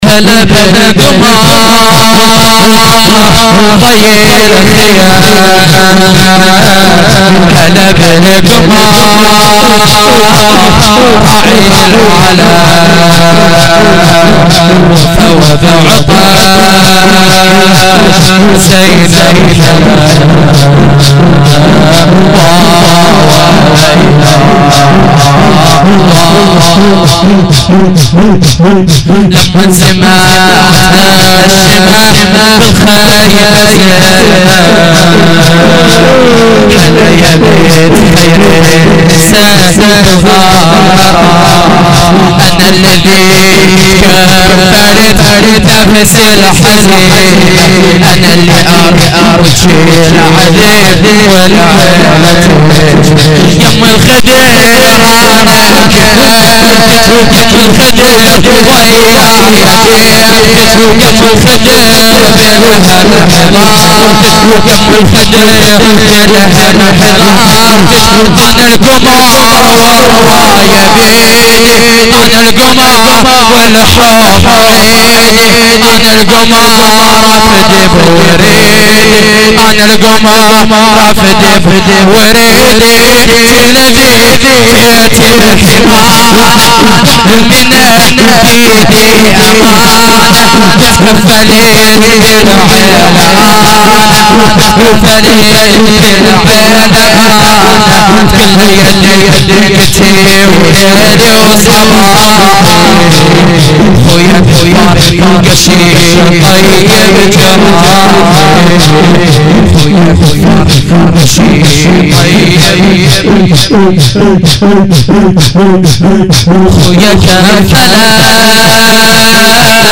shahadat-emam-reza-92-shor-arabi.mp3